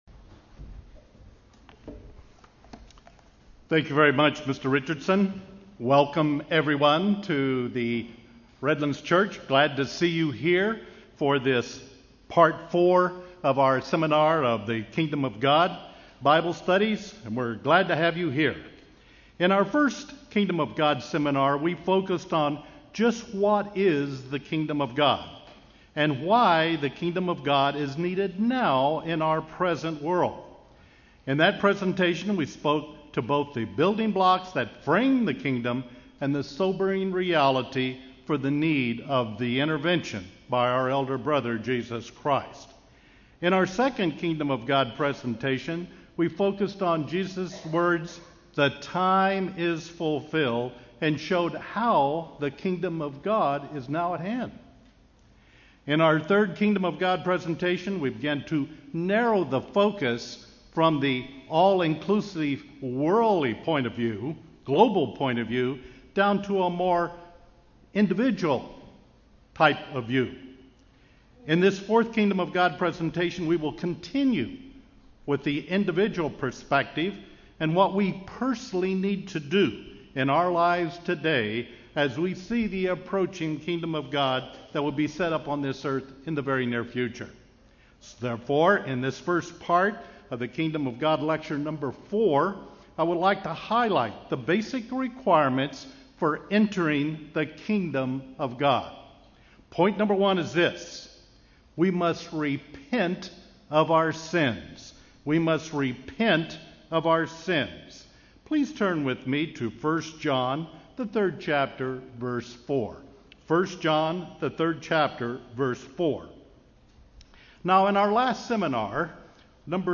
An individual perspective of the basic requirements to being a Christian: repentance of our sins, justification through Jesus Christ, and the need to be baptized. Learn more in this Kingdom of God seminar.